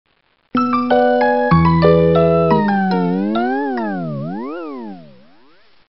С затуханием